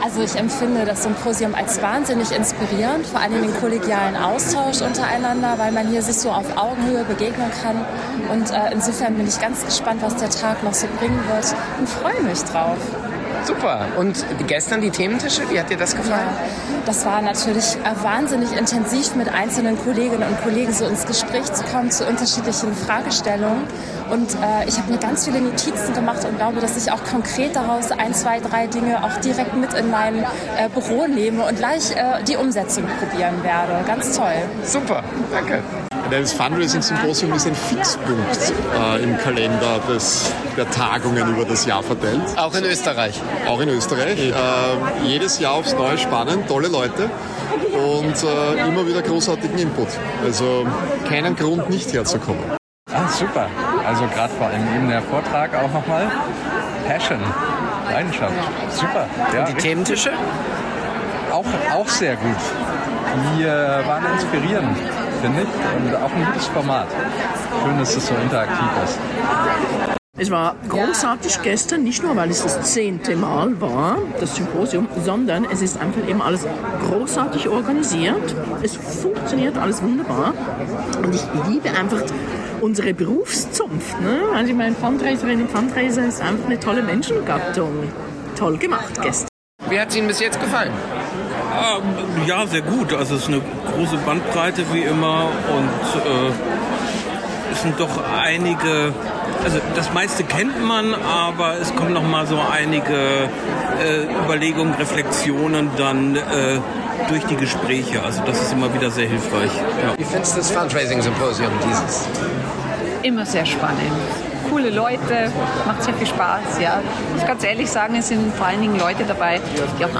Stimmen vom Fundraising Symposium in Frankfurt
Fünf Stimmen, fünf Eindrücke, eine Meinung: top!